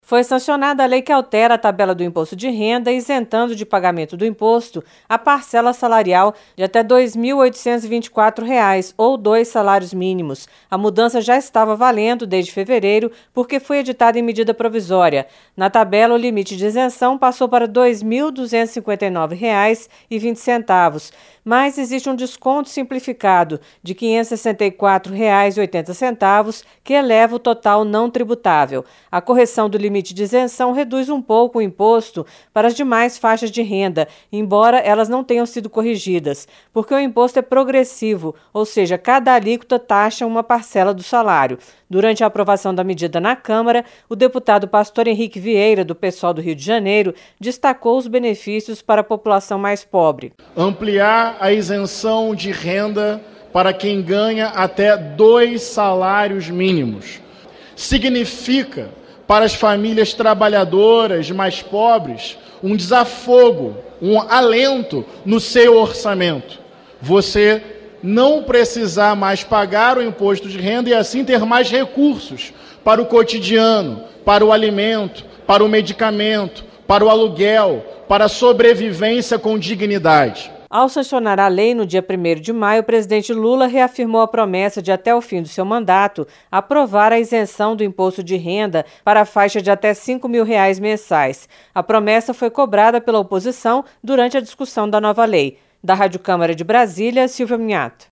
Sancionada lei com nova faixa de isenção do Imposto de Renda - Radioagência - Portal da Câmara dos Deputados